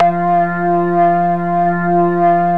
B3 CLICK.wav